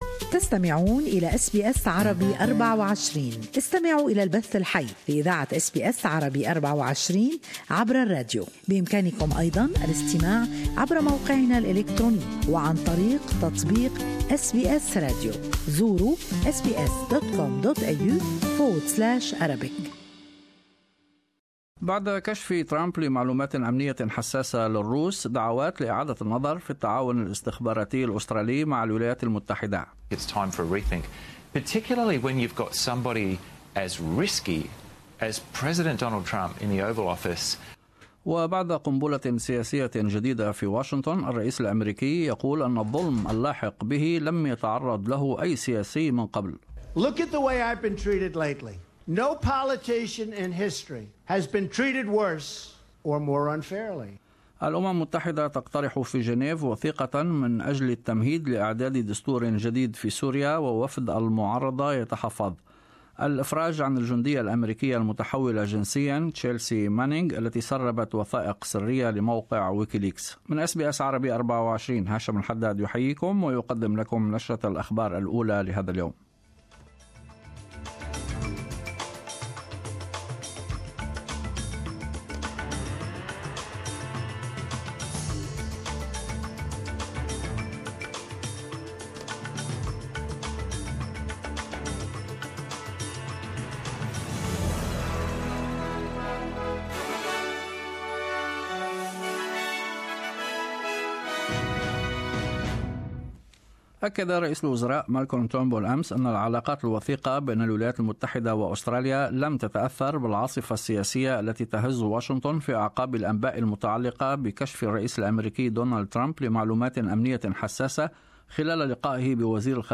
Latest Australian and world news in morning news bulletin.